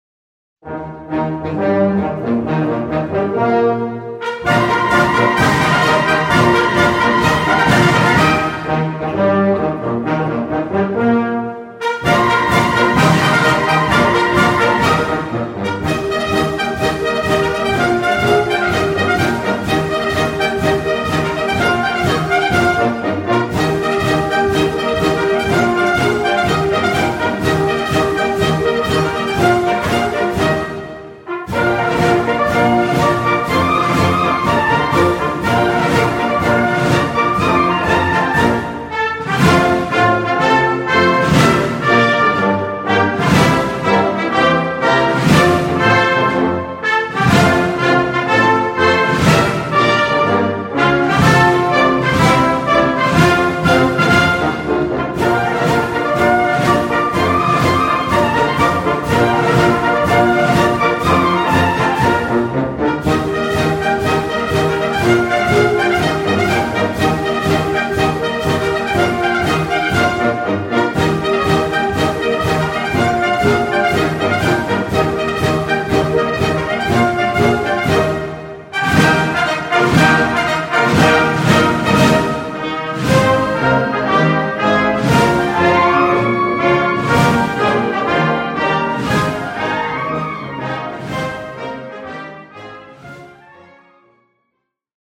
Répertoire pour Harmonie/fanfare - Défilé et parade